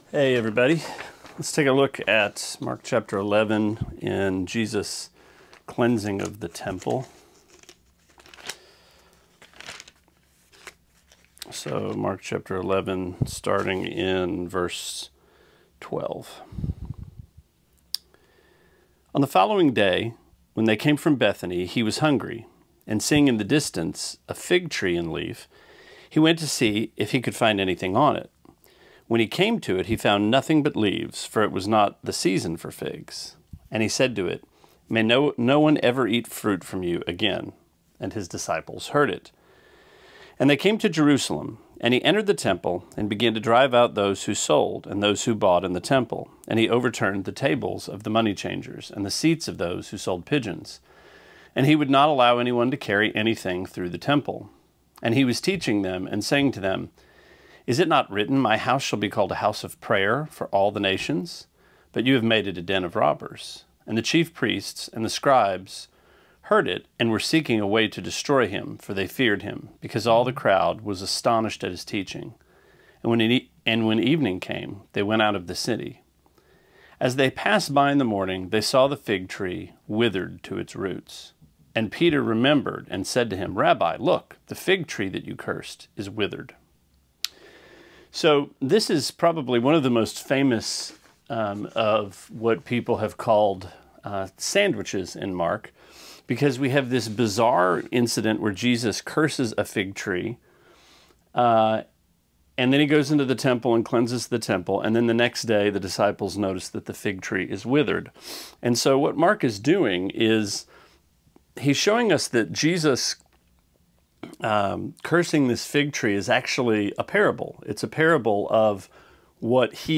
Sermonette 7/28: Mark 11:12-21: A Fig Sandwich